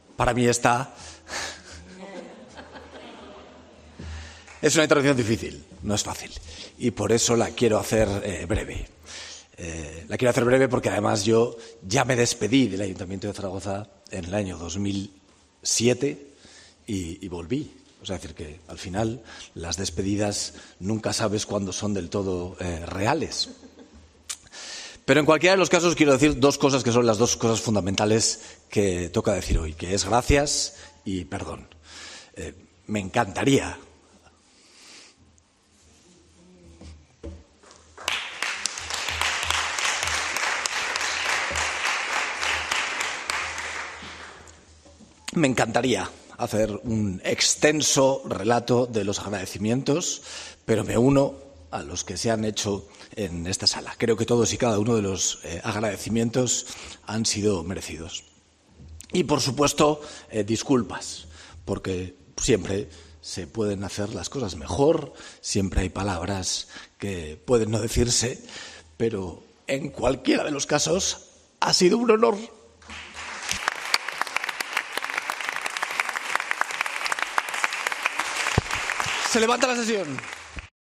Así de emocionado se mostró Jorge Azcón en el pleno extraordinario de despedida de esta legislatura.